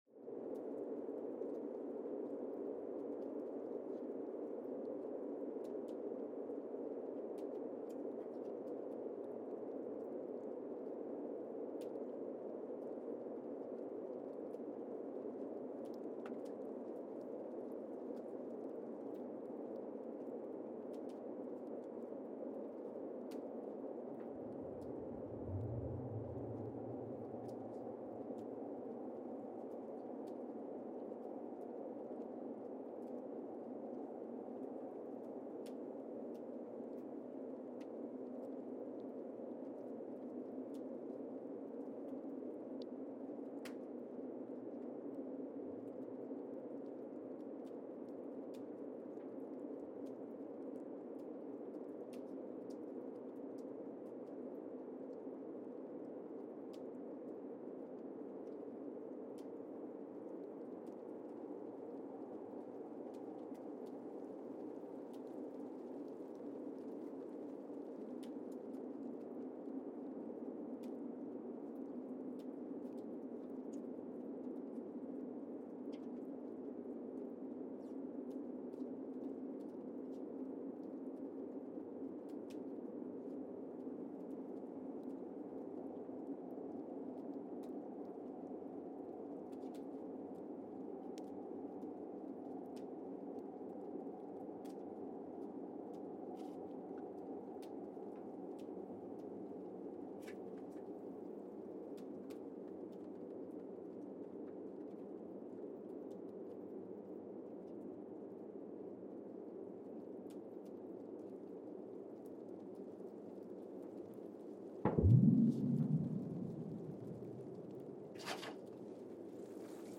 Casey, Antarctica (seismic) archived on May 1, 2025
Station : CASY (network: GSN) at Casey, Antarctica
Sensor : Streckheisen STS-1VBB
Speedup : ×1,800 (transposed up about 11 octaves)
Loop duration (audio) : 05:36 (stereo)
SoX post-processing : highpass -2 90 highpass -2 90